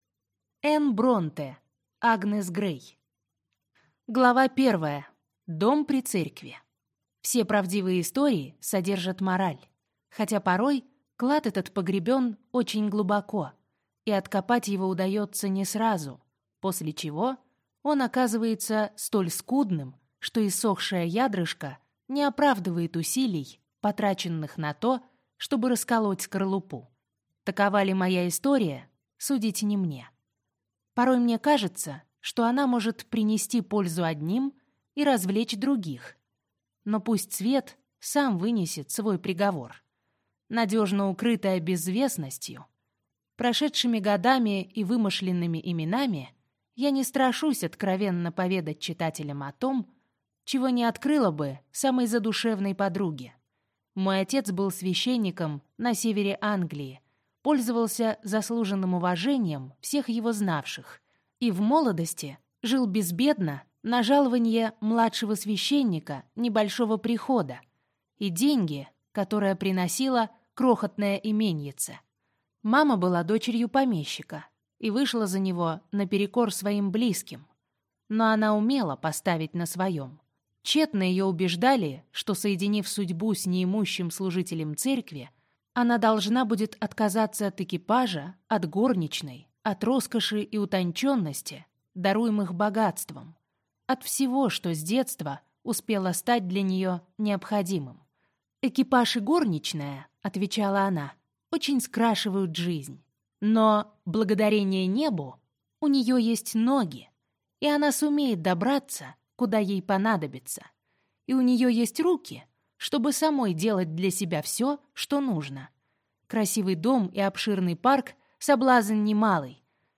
Аудиокнига Агнес Грей | Библиотека аудиокниг